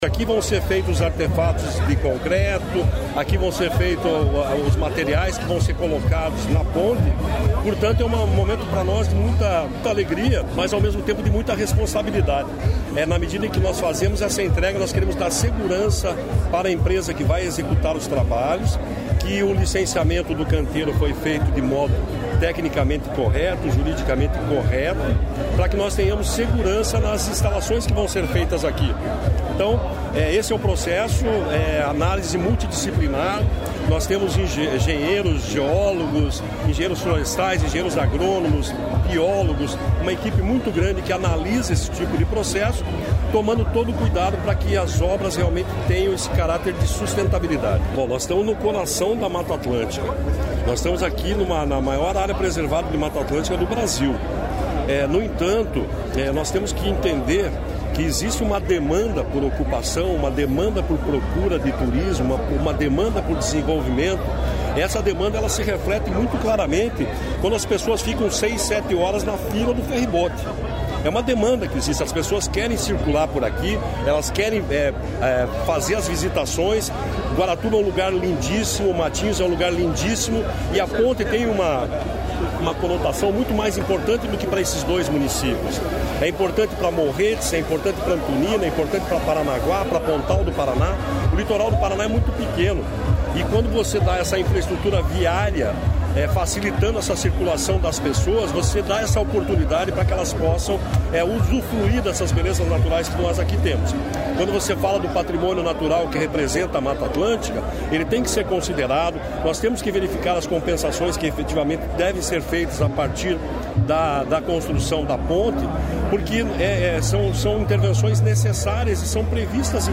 Sonora do presidente do IAT, Everton Souza, sobre o início da obra da Ponte de Guaratuba